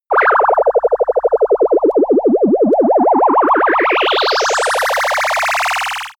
Gemafreie Sounds: Raumschiff